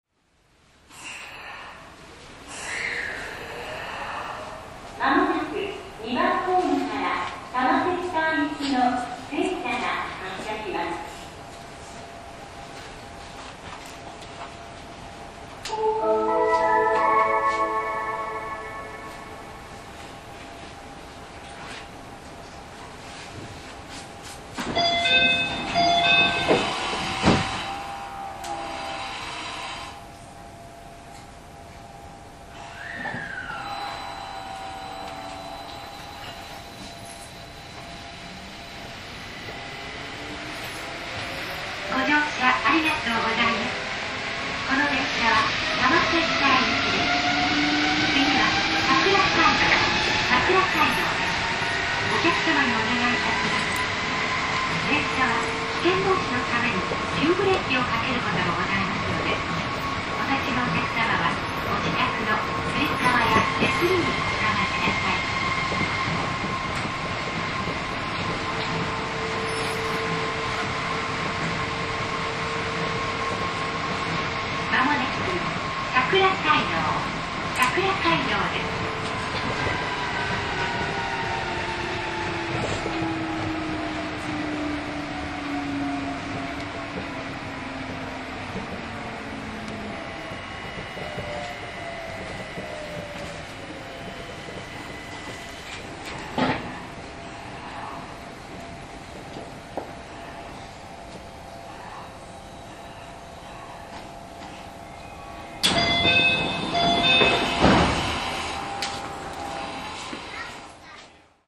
走行音
TM01 1000系 上北台→桜街道 1:51 10/10 --